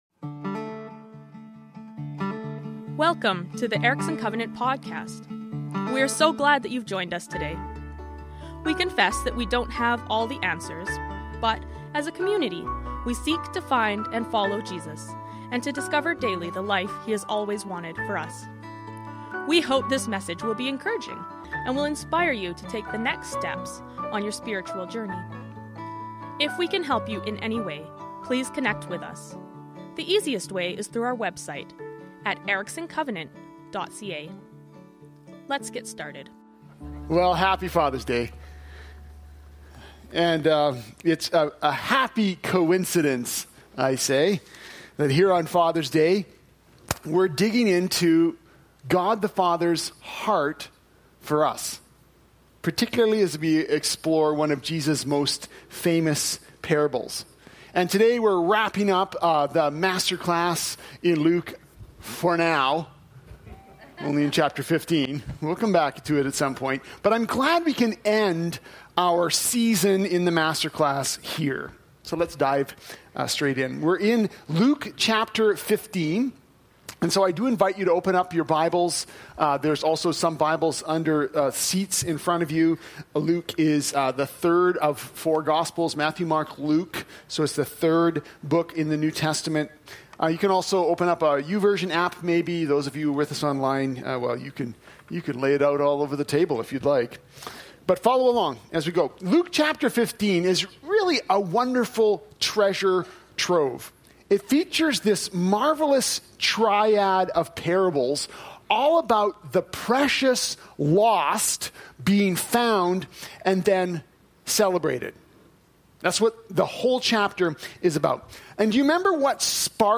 Erickson Covenant Church Message